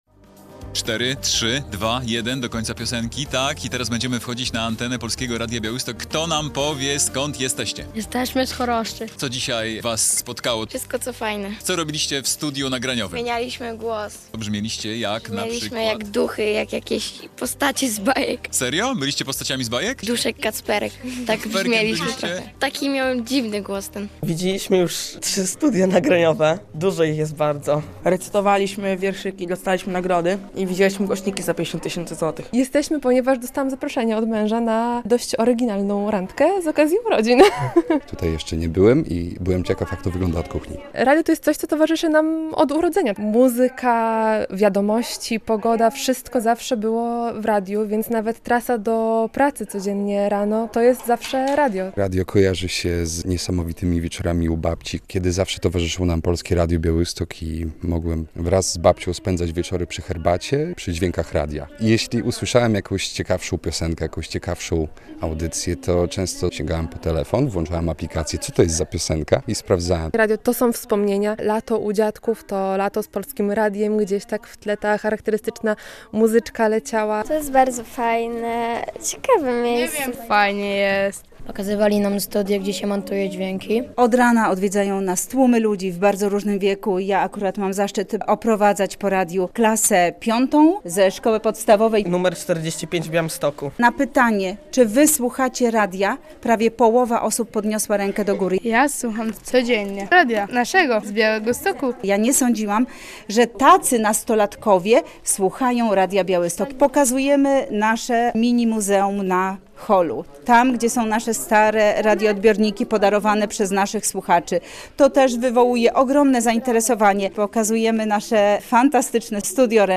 Dzień Otwarty w Polskim Radiu Białystok - relacja